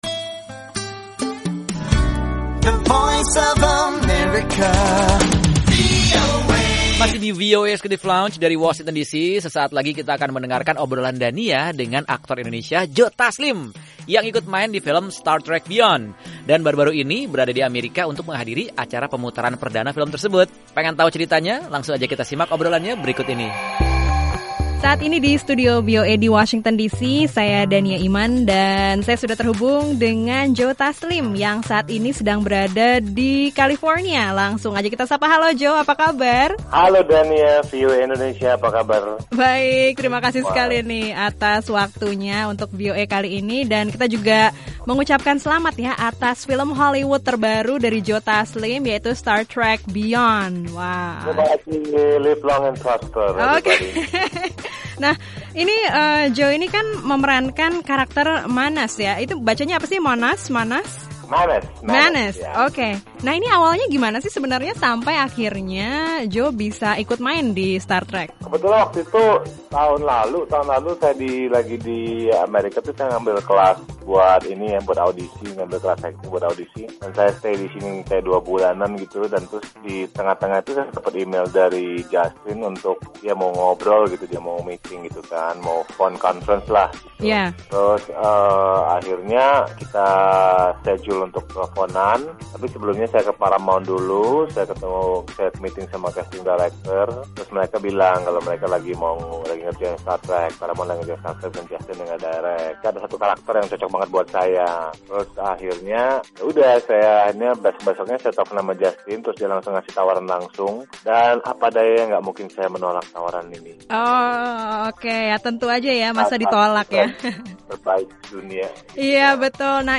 Simak obrolan reporter VOA